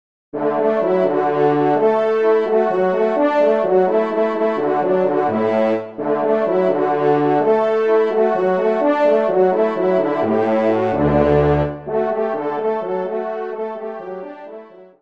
Trompe Basse